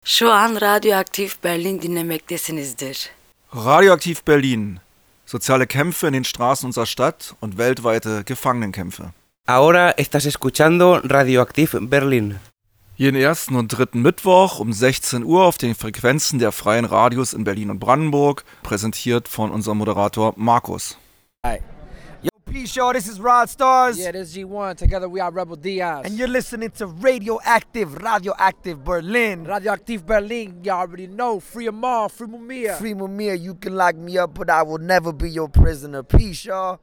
A recording of Radio Aktiv Berlin's radio show on this event can be heard here: